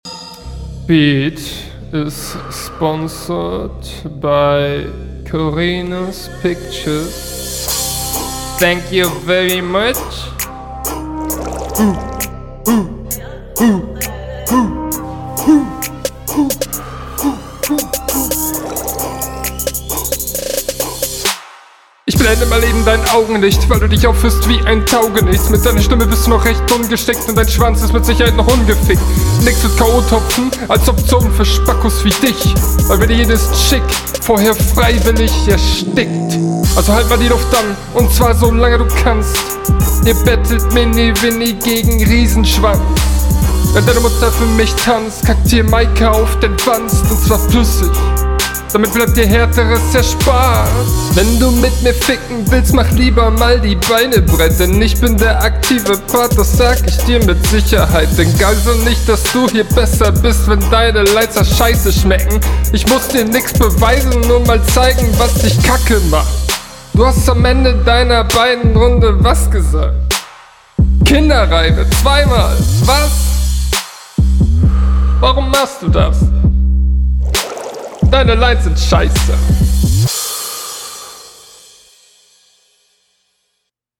Ich verstehe nichts.